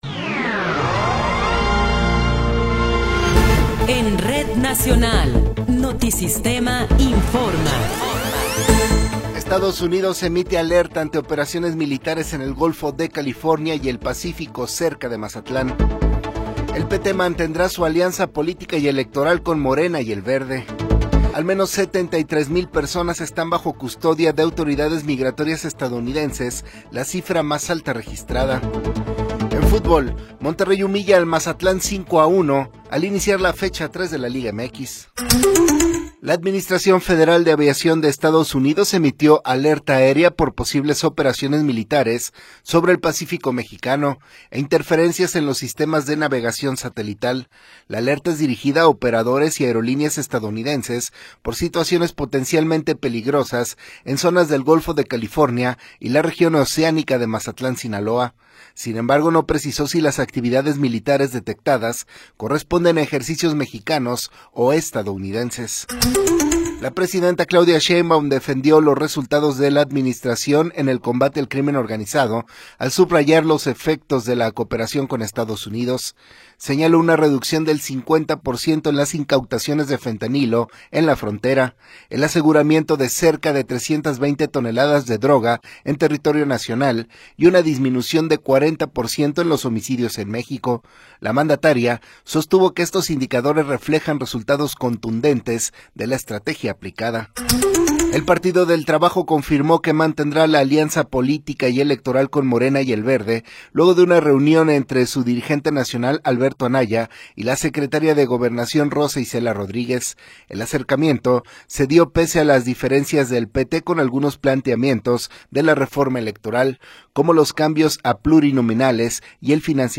Noticiero 8 hrs. – 17 de Enero de 2026
Resumen informativo Notisistema, la mejor y más completa información cada hora en la hora.